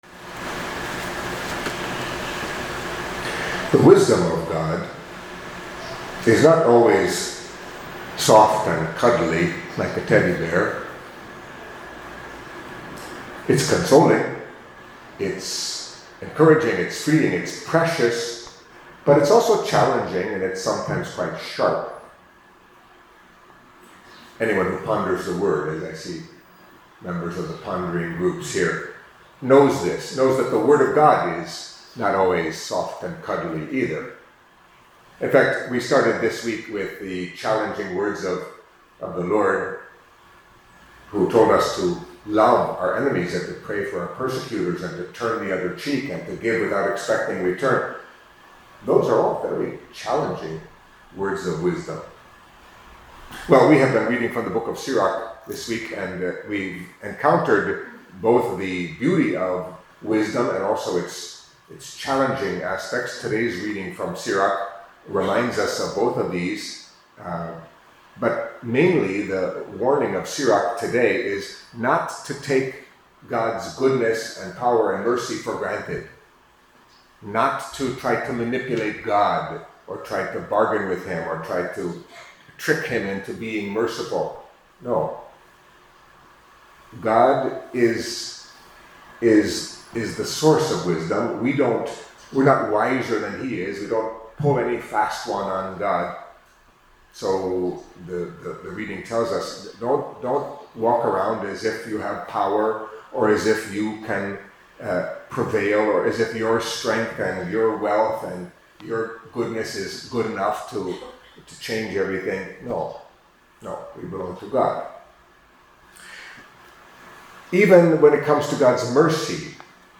Catholic Mass homily for Thursday of the Seventh Week in Ordinary Time